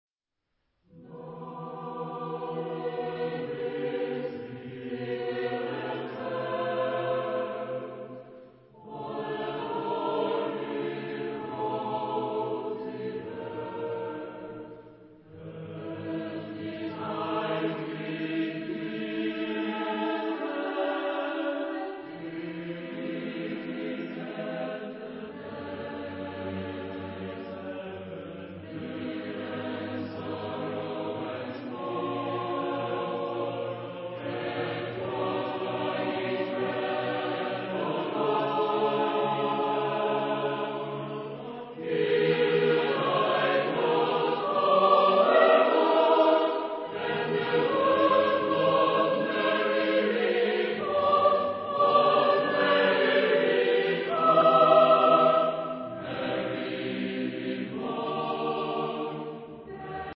Genre-Style-Forme : Pièce chorale ; Profane
Type de choeur : SATB  (4 voix mixtes )
Tonalité : libre